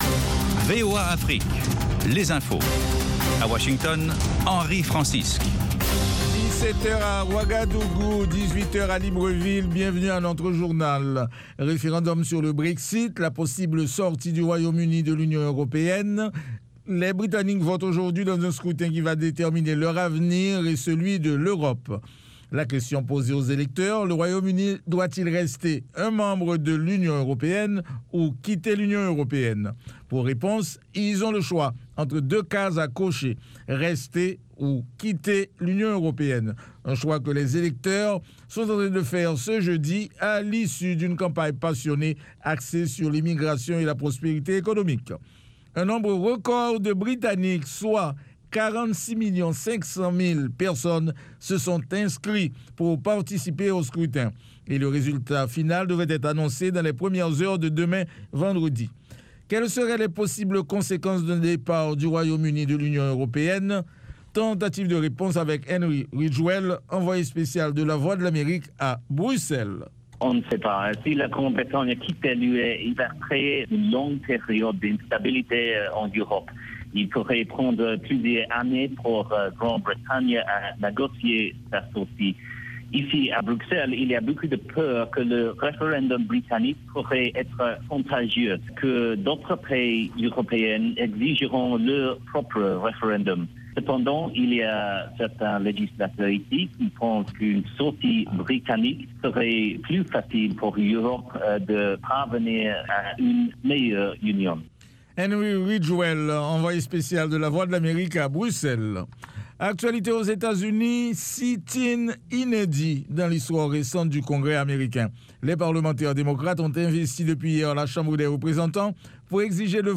10 Minute Newscast